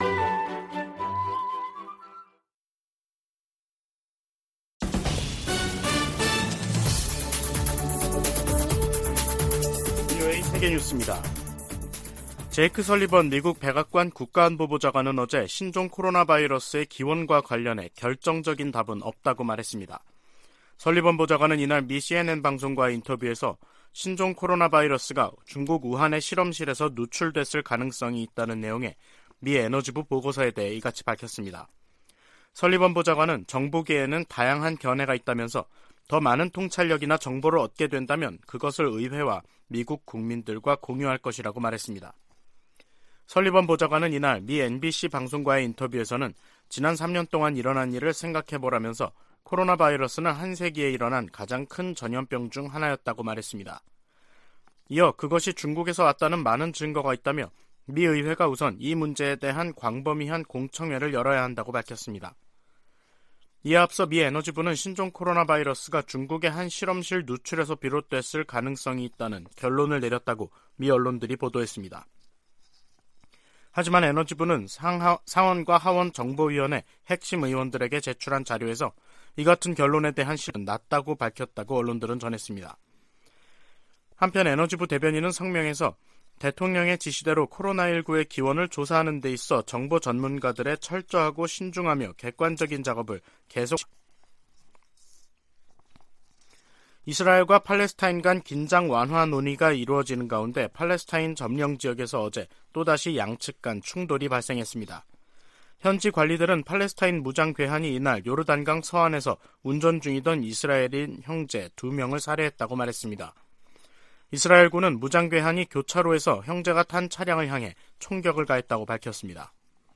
VOA 한국어 간판 뉴스 프로그램 '뉴스 투데이', 2023년 2월 27일 2부 방송입니다. 백악관은 러시아 용병조직 바그너 그룹에 북한이 무기를 지원했다고 거듭 비판했습니다. 미 국방부는 중국이 러시아 지원 카드를 완전히 내려놓지 않았다며 예의주시할 것이라고 밝혔습니다. 남-북한은 유엔총회에서 바그너 그룹에 대한 북한의 무기거래 문제로 설전을 벌였습니다.